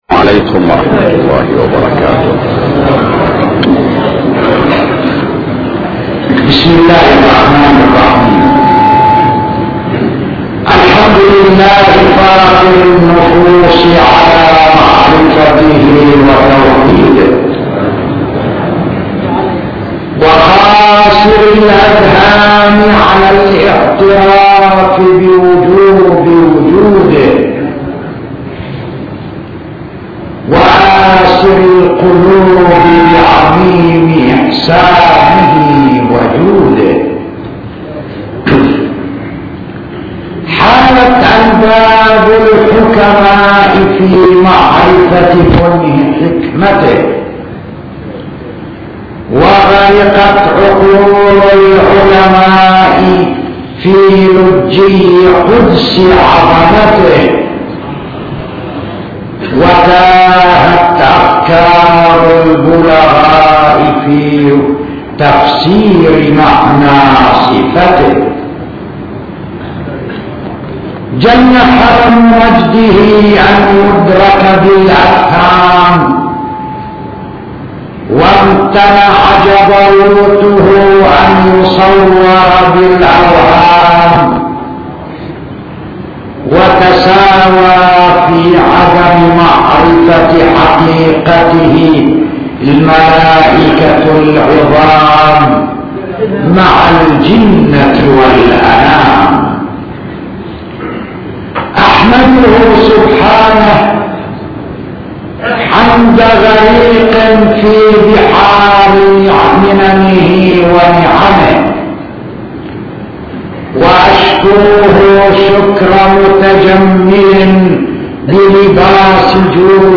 صوتيات | خطب